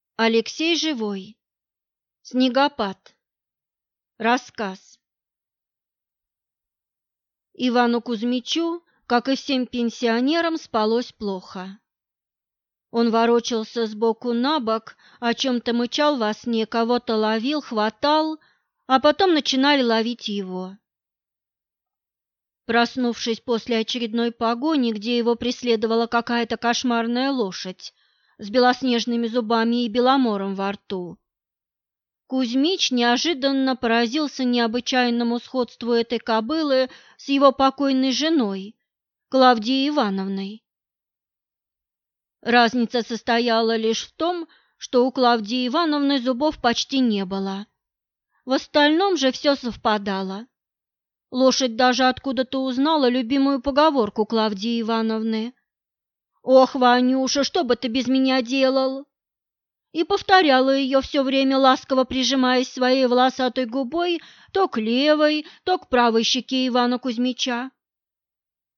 Аудиокнига Снегопад | Библиотека аудиокниг